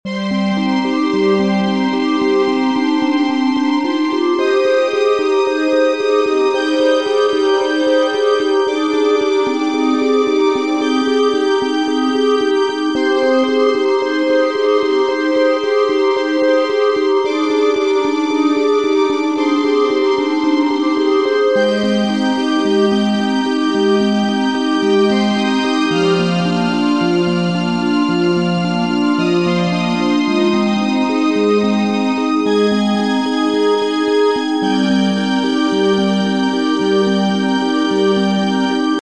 水の流れをイメージした（つもり）ゆったりと時間が過ぎてゆく。